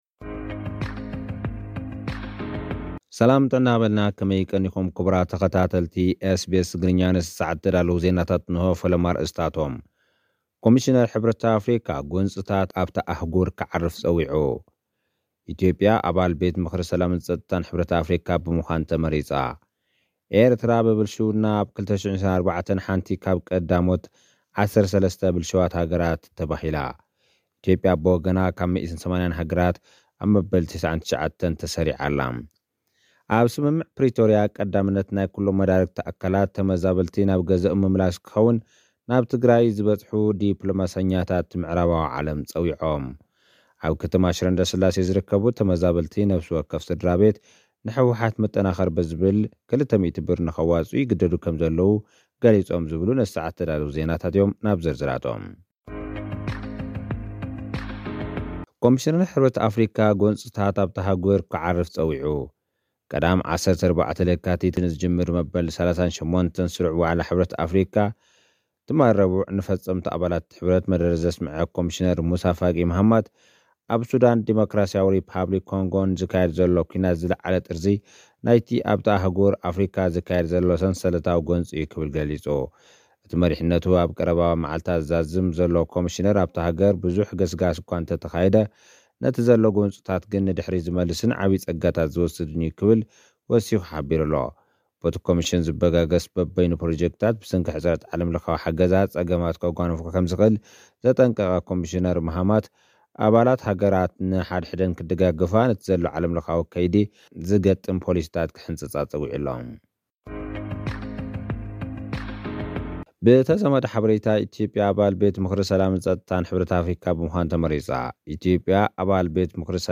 ኣብ 2024 ካብ 180 ሃገራት ተርታ ሃገራት ብልሽውና፡ ኤርትራ ካብ ቀዳሞት 13 ኢትዮጵያ ድማ ኣብ መበል 99 ተሰሪዐን። (ጸብጻብ)